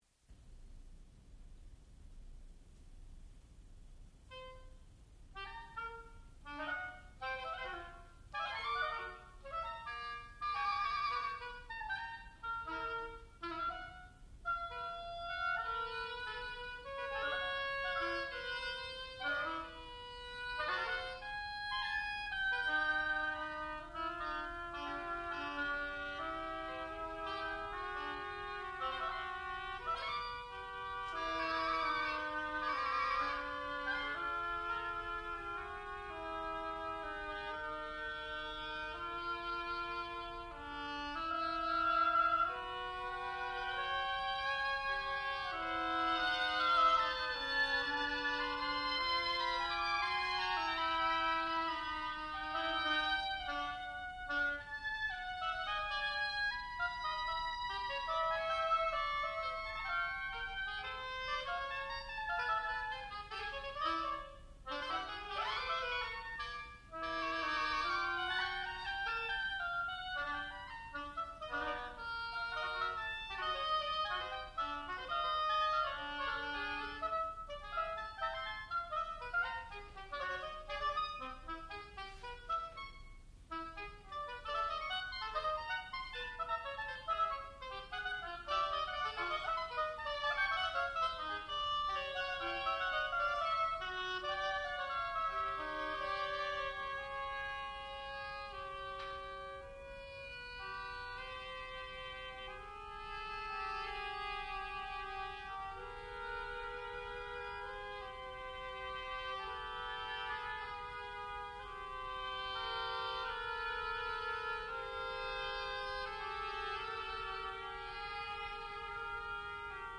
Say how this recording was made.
Graduate Recital